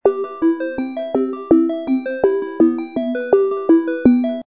ringtone.mp3